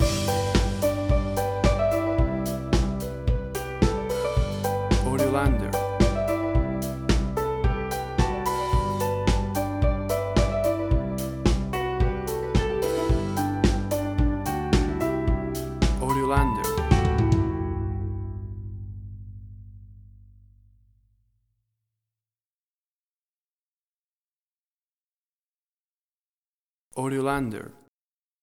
WAV Sample Rate: 16-Bit stereo, 44.1 kHz
Tempo (BPM): 110